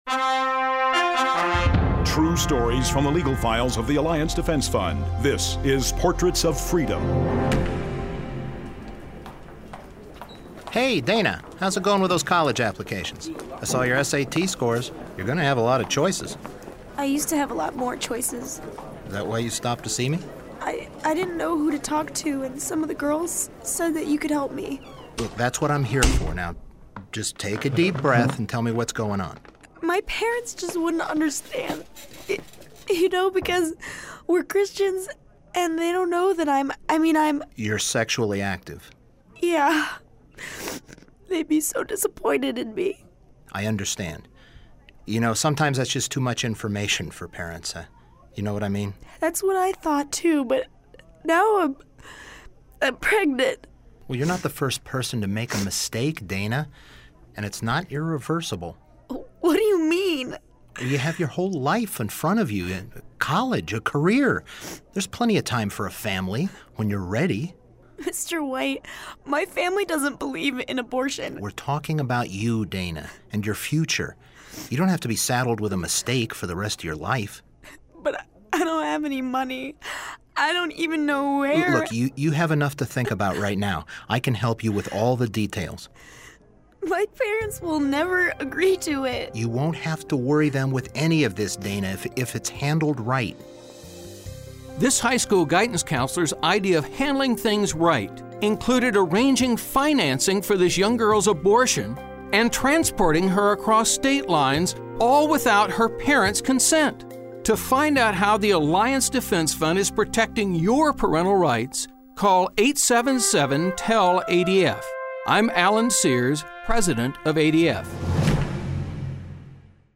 ADF Radio Clip: